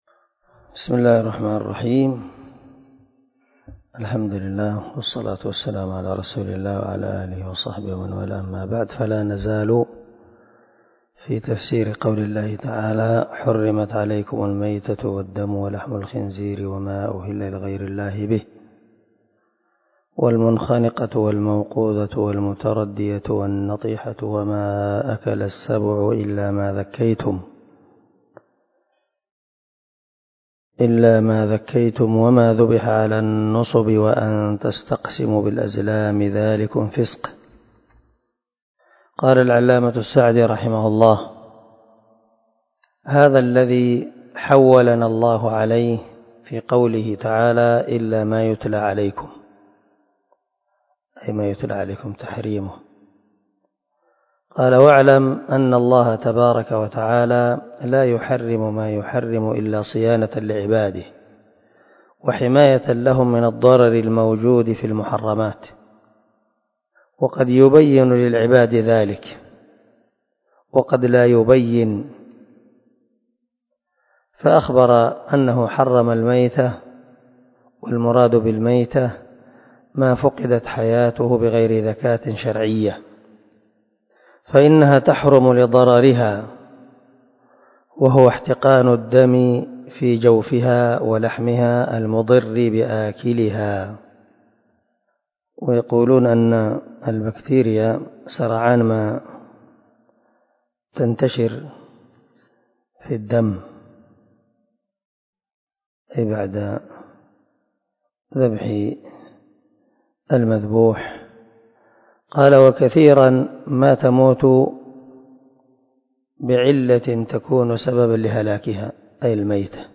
337الدرس 4 تابع تفسير آية ( 3 ) من سورة المائدة من تفسير القران الكريم مع قراءة لتفسير السعدي